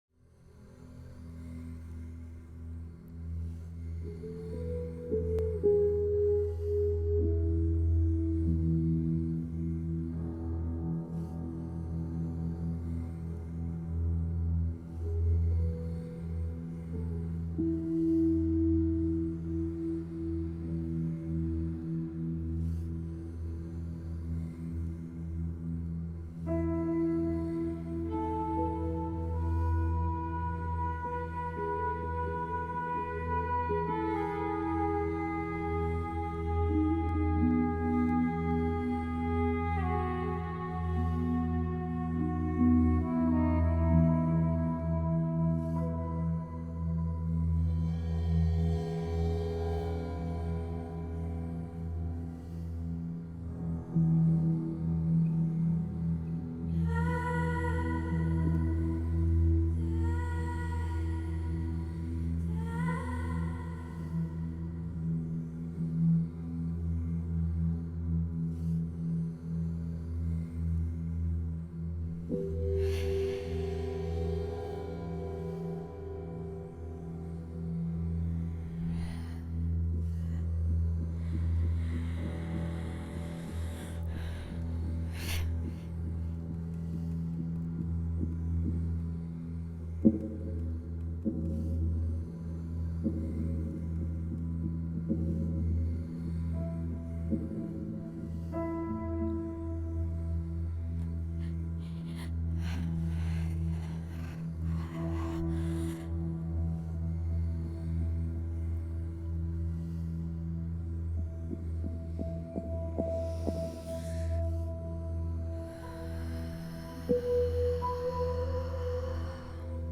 ambiance.ogg